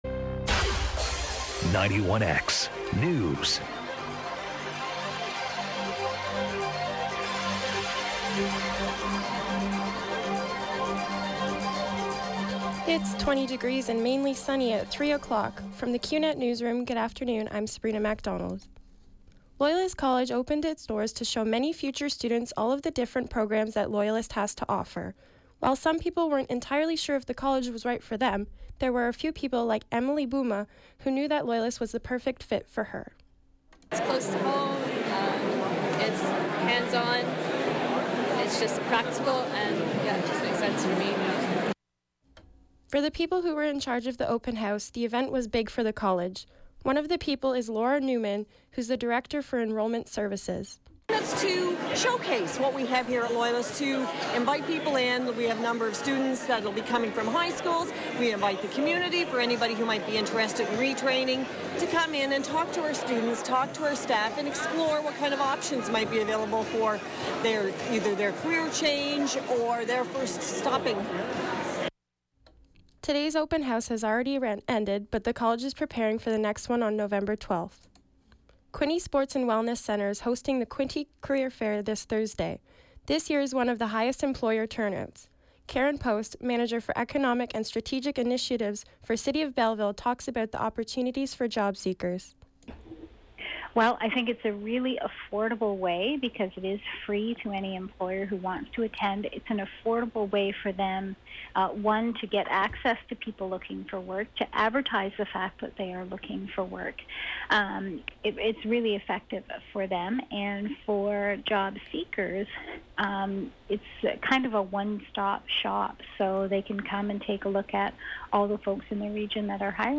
91X Newscast – Wednesday, Oct. 5, 2016, 3 p.m.
newscast-oct5-3pm.mp3